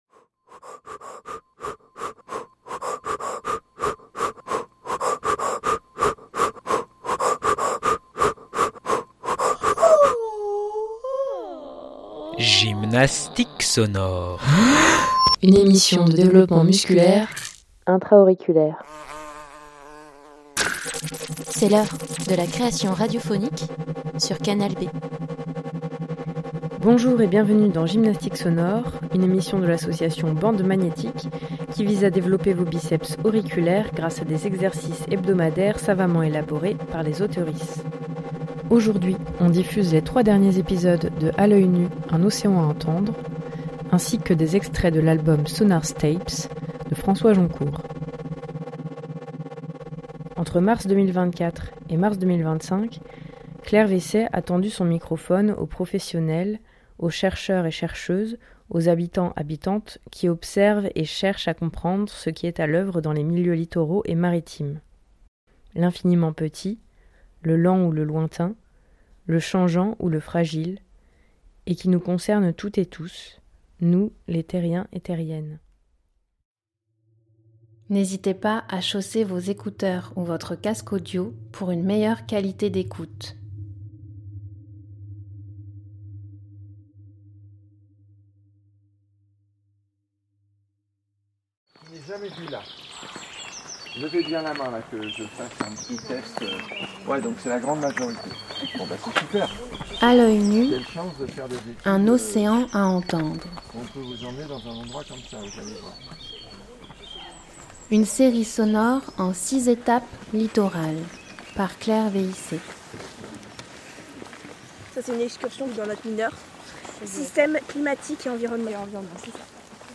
Série sonore en 6 étapes littorales du parc naturel marin de l'estuaire de la Gironde et de la mer des Pertuis
Dans cette émission, écoutez les 3 dernières étapes : Phare de la Coubre, Phare de Grave, Bac Blaye-Lamarque.
Entre mars 2024 et mars 2025, j'ai tendu mon microphone aux professionnel·les, aux chercheurs·ses, aux habitant·es qui observent et cherchent à comprendre ce qui est à l’œuvre dans ces milieux littoraux et maritimes : l’infiniment petit, le lent ou le lointain, le changeant ou le fragile, et qui nous concernent toutes et tous, nous les terrien·nes.